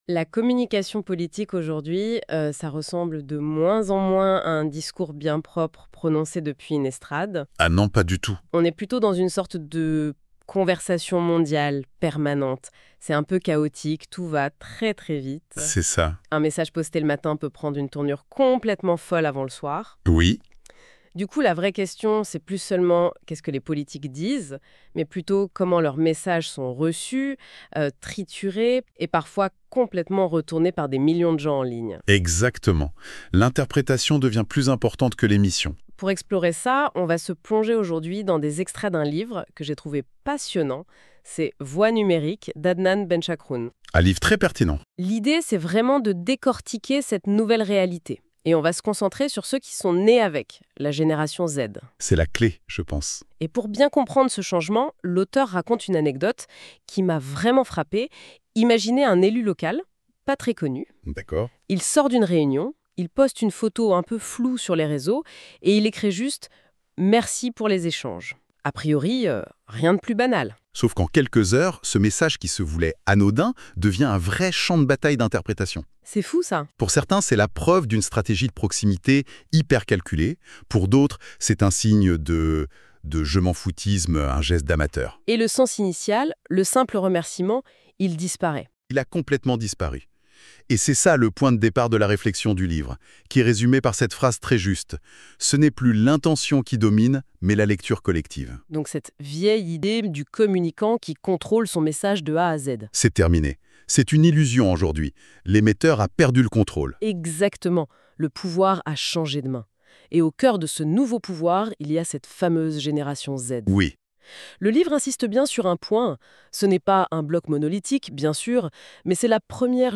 Débat en Podcast de la Web Radio R212 | Téléchargements | L'Opinion DJ Gen X,Y et Z
Les débats en podcast des chroniqueurs de la Web Radio R212 débattent de différents sujets d'actualité